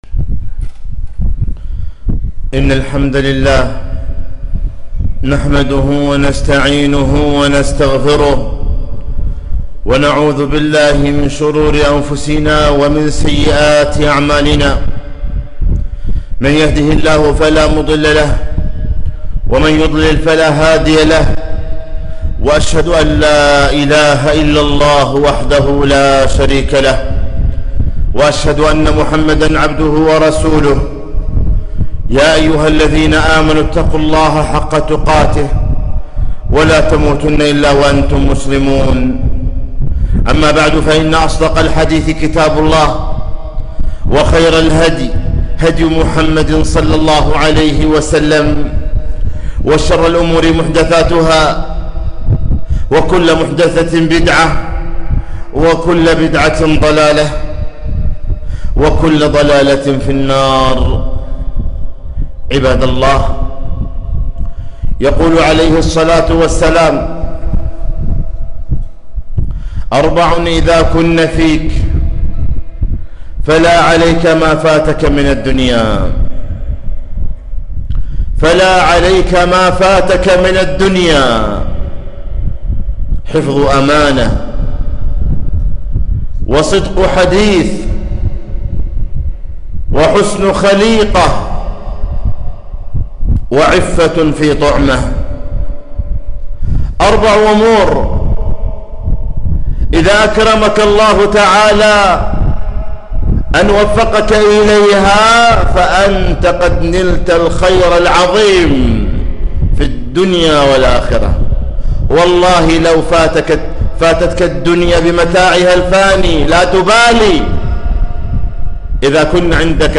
خطبة - حفظ الأمانة